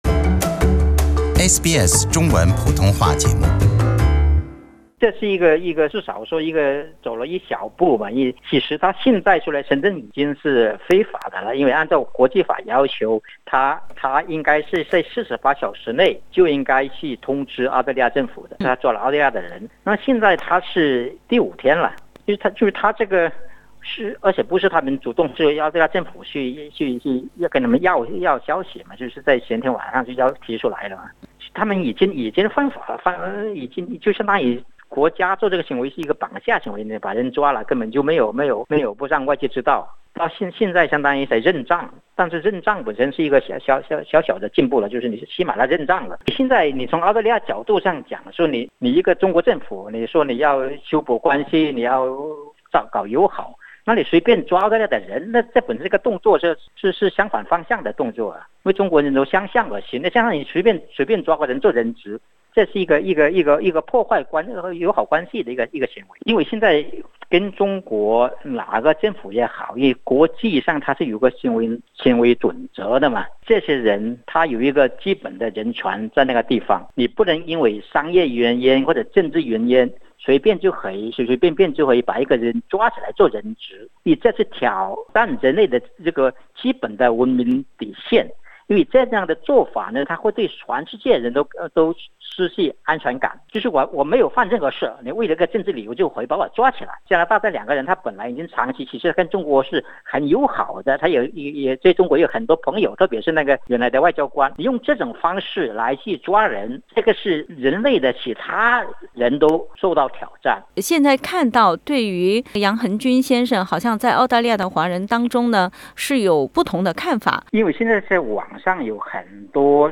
（採訪內容僅代表嘉賓觀點，不代表本台立場） 關注更多澳洲新聞，請在Facebook上關注SBS Mandarin，或在微博上關注澳大利亞SBS廣播公司 READ MORE 澳防长访京之前 中国拘捕澳洲公民杨恒均 中国证实已拘捕澳籍华裔作家杨恒均 【杨恒均失踪】好友冯崇义：中国向澳洲施压 冯崇义博士评杨恒均失联：中国为孟晚舟案在向澳洲政府施压 澳籍华裔作家杨恒均在中国失踪 友人恐其已被国安拘留 报道：华裔澳籍作家杨恒均中国失踪 分享